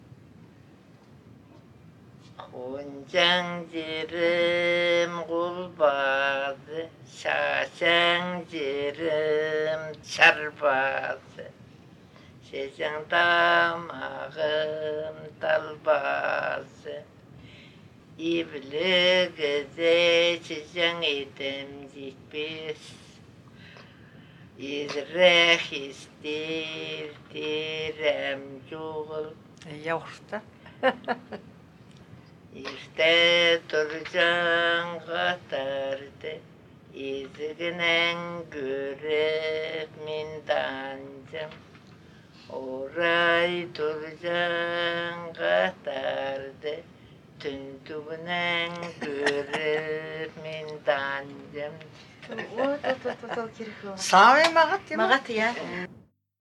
Первый связан со общей стилистикой типовых для данной локальной традиции напевов, второй является самостоятельным.
Интонирование некоторых образцов приводит к размыванию мелодического контура, важным оказывается сохранение общего направления движения мелодии, а не конкретная точная высота отдельных ее звуков.
01 «Хозанахтыӊ сарыны» — песня зайчика
из с. Нижняя Тёя Аскизского р-на Республики Хакасия